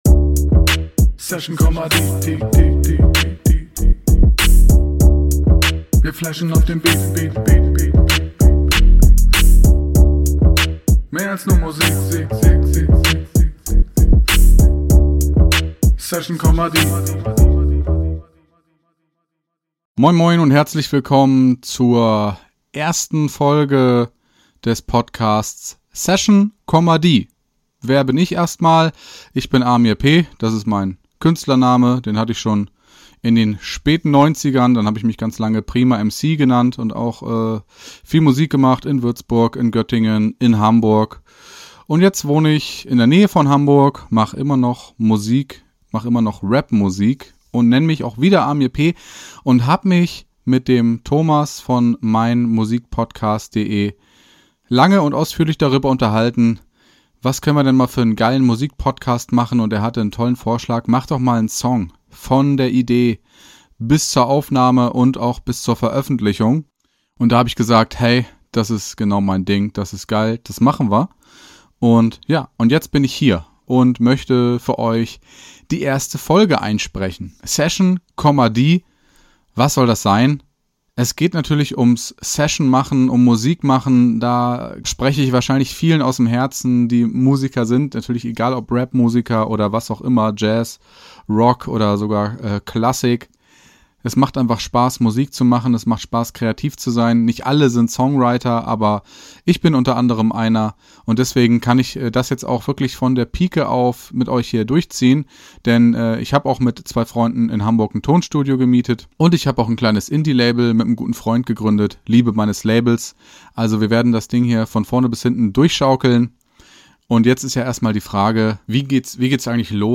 November 2020 Nächste Episode download Beschreibung Kapitel Teilen Abonnieren Session, die --- Ein Podcast über die Entstehung eines Rap Songs, der am Ende weit über das Hiphop-Genre hinausgehen wird. Von der Idee bis zur Veröffentlichung.